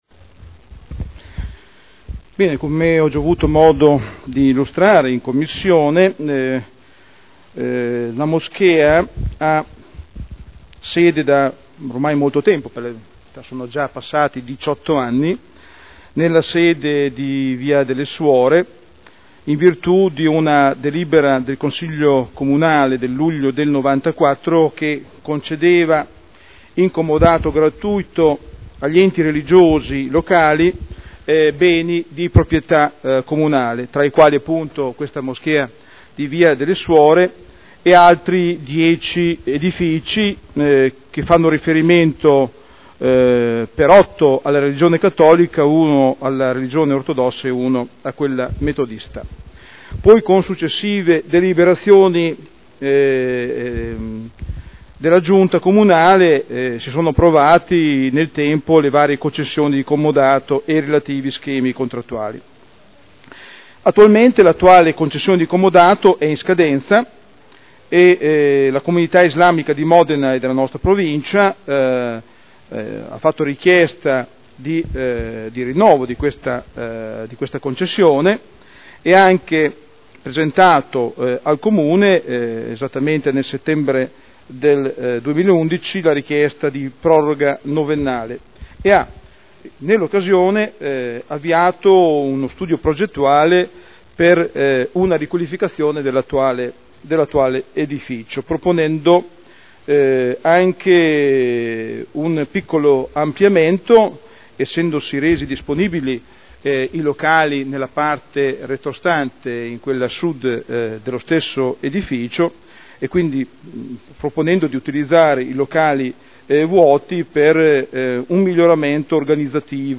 Daniele Sitta — Sito Audio Consiglio Comunale
Seduta del 20/02/2012.